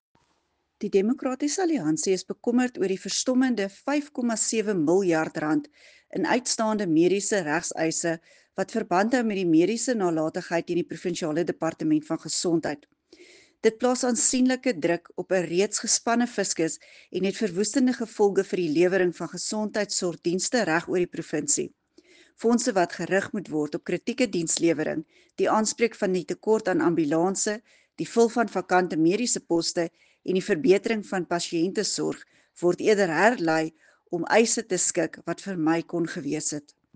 Afrikaans soundbite by Dulandi Leech MPL.